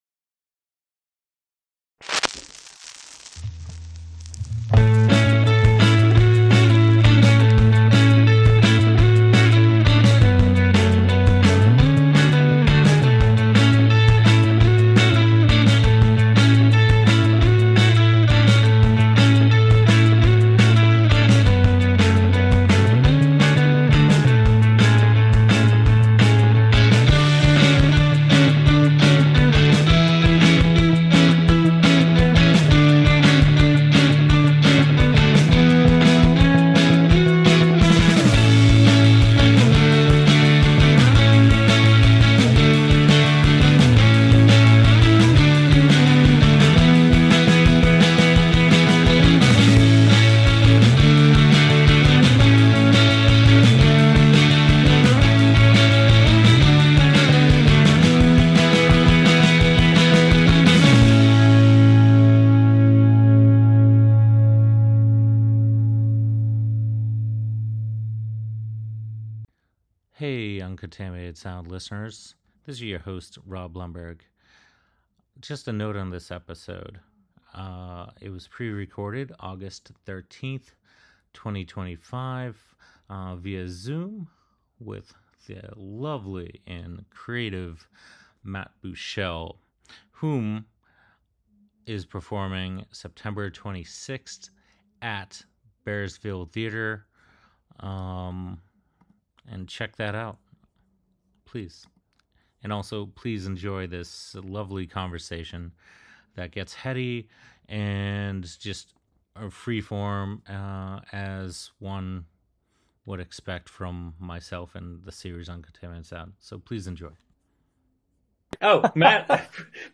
Show notes: pre-recorded 08/13/25
What started as a photography series then video series, "Uncontaminated Sound" is a conversation series for radio that follows comedians, musicians, fine artists, and actors with the primary objective to gain a particular insight into their creative processes which can only be fully unravelled by truly going behind the scenes.
These features offer listeners real, raw, and authentic conversations.